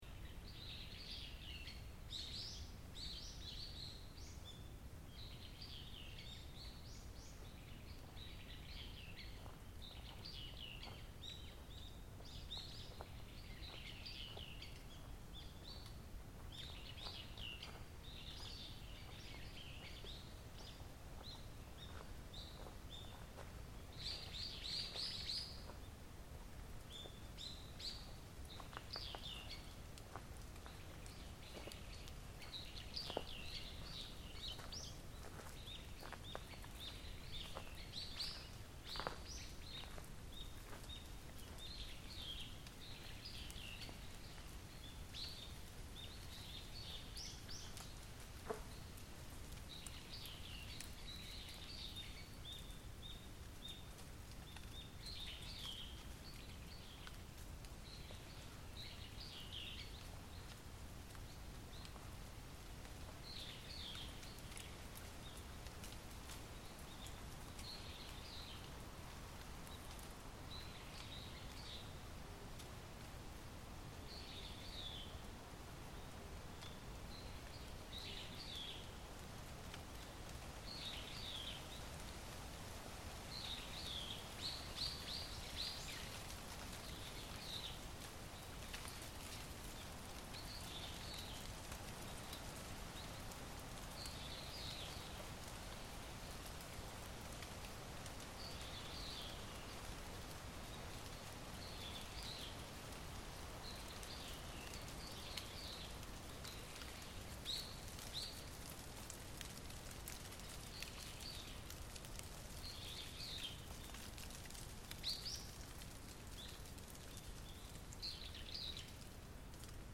Hike a little beyond the main section of Nara Park in Japan, and you enter the Kasugayama Primeval Forest – also part of the Historic Monuments of Ancient Nara UNESCO site. If you are there during the off season and early enough in the morning, it is possible to hear the forest itself, as the monks and pilgrims of old must have done. This recording was made on 13 December, 2024 on the popular hiking trail that winds up to the summit of Wakakusayama Hill. Although there is a background hum from the city, it primarily captures the sounds of the forest – bird song, trees rustling and wind – but at the start of the recording you can also hear the footsteps of a single hiker on the gravel path as they pass me.